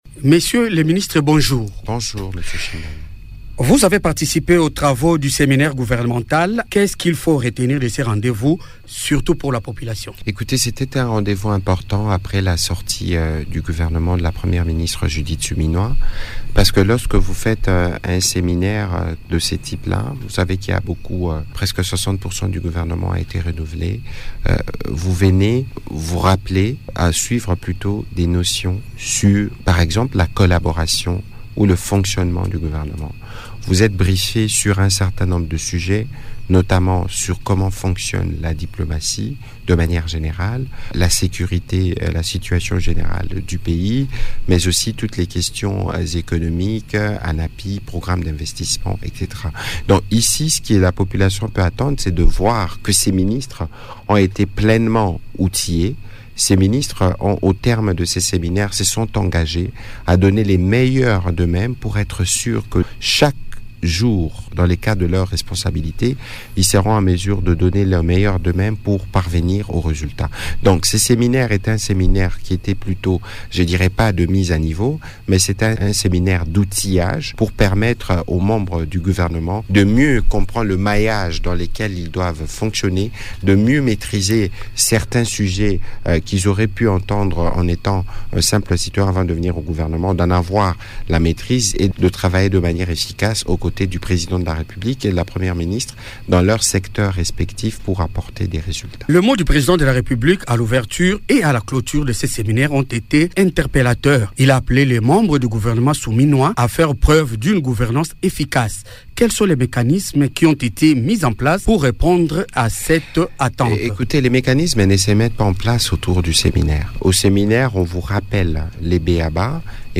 « Ce que la population peut attendre c’est de voir que ses ministres ont été pleinement outillés et se sont engagés, au terme de ce séminaire, à donner le meilleur d’eux-mêmes pour être surs que chaque jour, dans le cadre de leur responsabilité, ils seront à mesure de donner les meilleurs d’eux-mêmes pour parvenir aux résultats », a rapporté Patrick Muyaya, lors d'une interview ce jeudi 25 juillet à Radio Okapi.